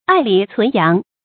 愛禮存羊 注音： ㄞˋ ㄌㄧˇ ㄘㄨㄣˊ ㄧㄤˊ 讀音讀法： 意思解釋： 由于愛惜古禮，不忍使它廢弛，因而保留古禮所需要的祭羊。